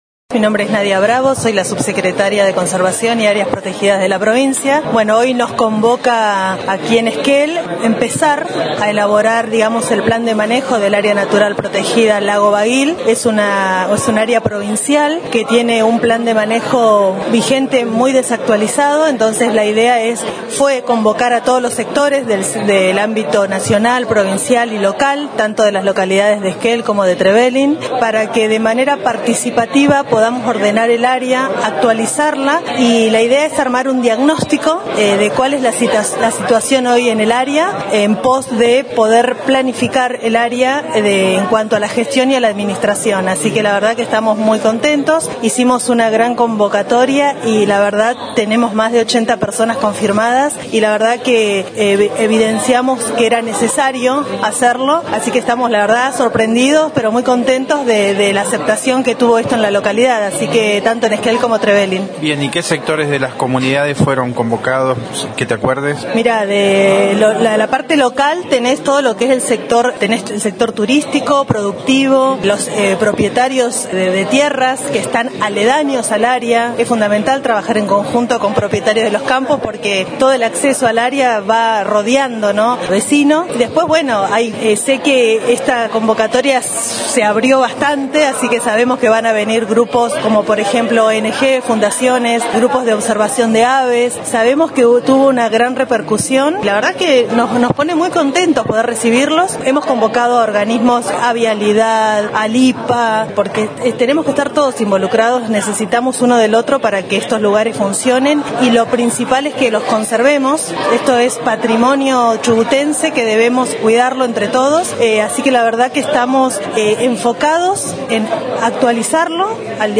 Bravo valoró en diálogo con el móvil de Radio Nacional la participación de sectores y vecinos interesados en este tema, para actualizar el plan de manejo de Bagillt. También fue consultada sobre los rumores acerca de un nuevo intento de avanzar con el cuestionado proyecto de una central hidroeléctrica en el Río Bagillt.
Nadia Bravo como Subsecretaría de Conservación y Áreas Protegidas de Chubut